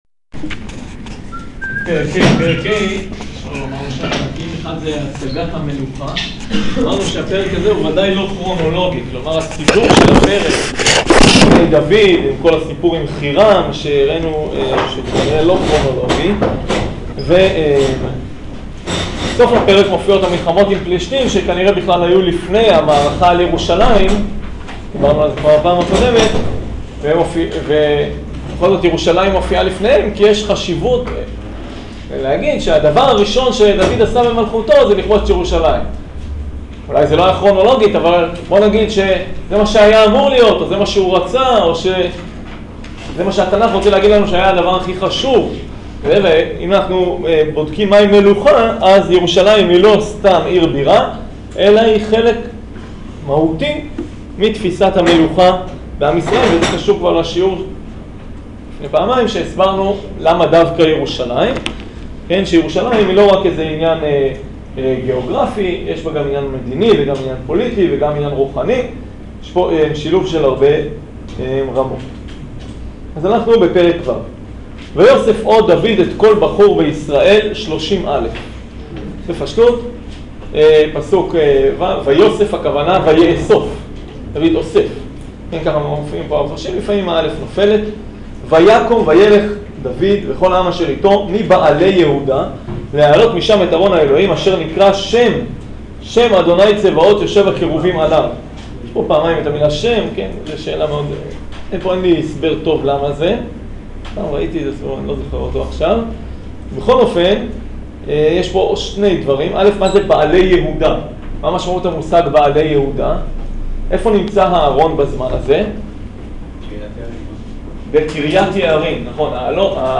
שיעור שמואל ב' פרק ו'